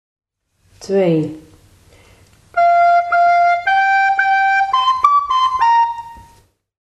Opa’s notenboom en een team van 4 zingende cachers leidt tot een puzzel, die om noten draait.
Ieder fragmentje is het begin van een, nog steeds bekend Nederlandstalig kinderliedje uit de oude doos.